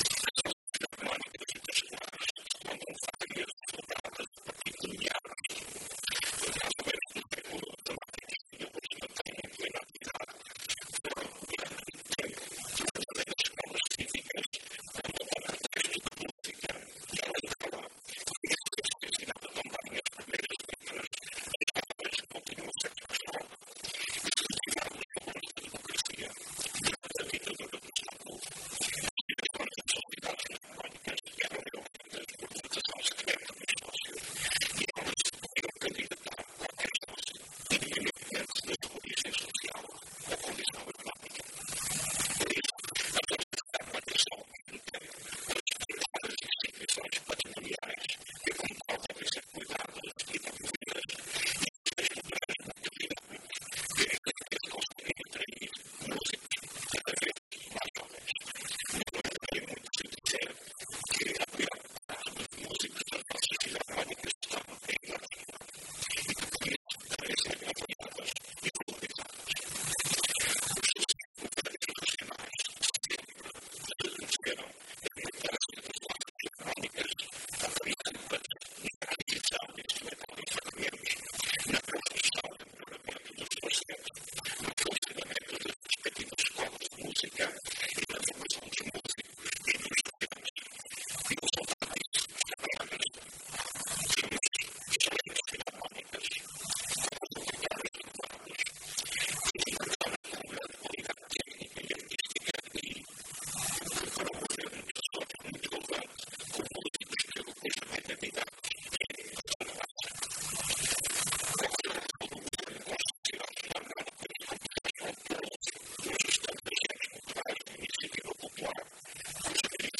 Luiz Fagundes Duarte, que falava no debate de um projeto de diploma sobre o apoio às atividades desenvolvidas pelas filarmónicas no arquipélago, frisou que se pretende com estes apoios “valorizar e promover” estas instituições recreativas “de caráter popular, provavelmente mais antigas, mais permanentes e mais intervenientes na vida cultural e social" das ilhas açorianas, sobretudo nos meios rurais.